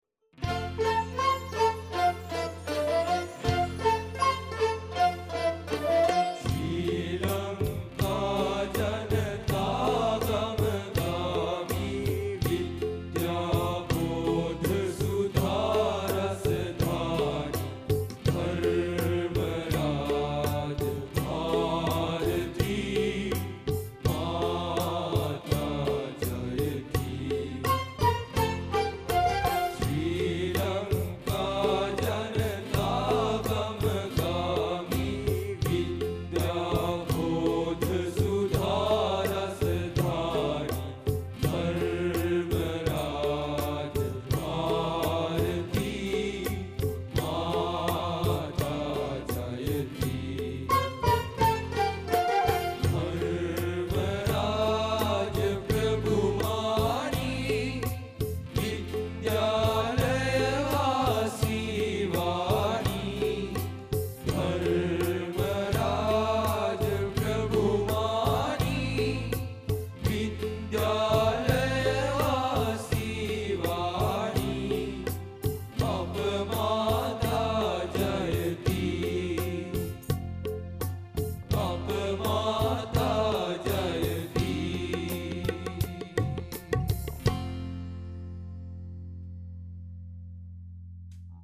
Susara Musical Show 2018 produced a School Song while considering the standardized song